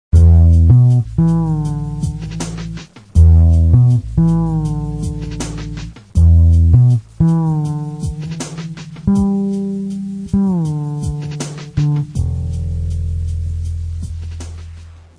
So klingt der Vester-E-Bass ohne Effekt-Gerät Seit einiger Zeit spiele ich auch einen Stagg E-Bass (fretless, viersaitig, passiv).
fretless.wma